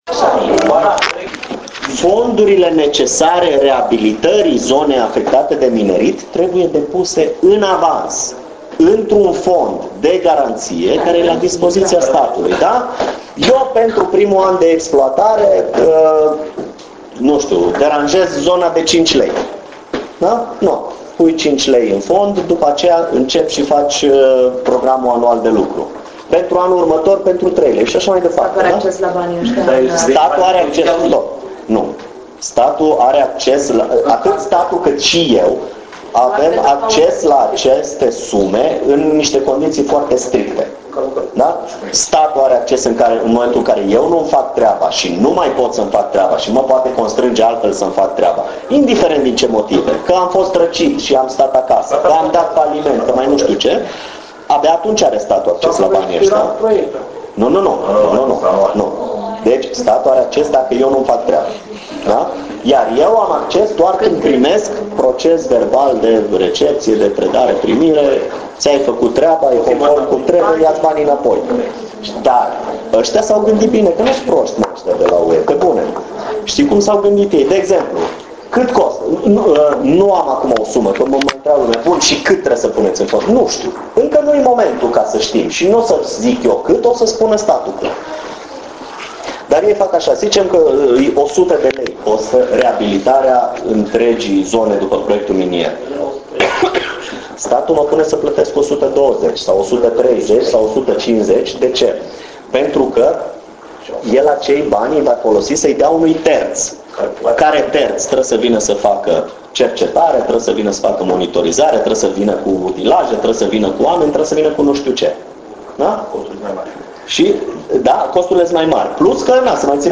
Vizita de lucru la Rosia Montana, a unui grup de organizaţii neguvernamentale şi reprezentanţi ai societăţii civile din Botoşani şi Suceava, organizată de Grupul de Susţinere a Proiectului Roşia Montană. Obiectul vizitei - analiza starii economice si sociale actuale a comunitatii locale, mediului si obiectivelor de patrimoniu cultural, precum si pentru a afla care vor fi efectele implementarii proiectului minier in aceste domenii.